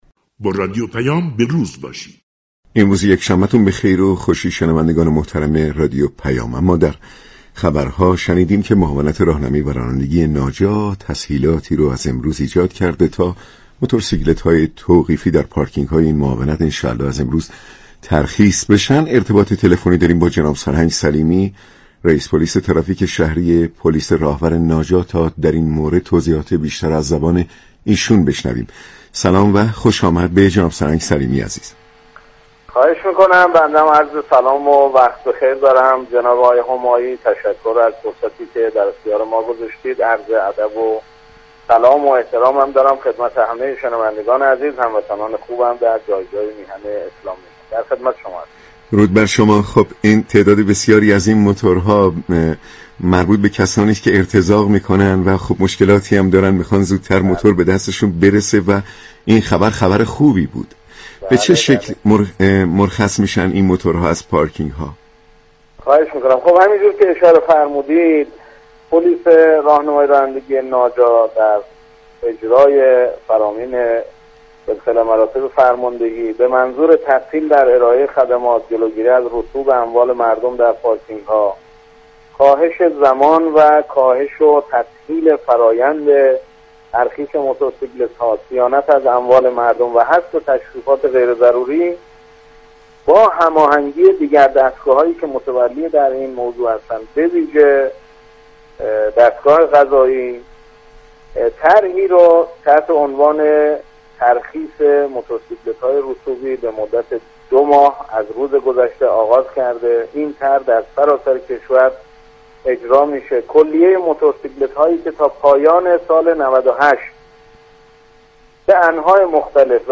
سرهنگ سلیمی، رئیس پلیس ترافیك شهری راهور ناجا در گفتگو با رادیو پیام ، از تسهیلات ویژه پلیس برای ترخیص موتورسیكلت‌های توقیفی در پاركینگ‌ها خبر داد.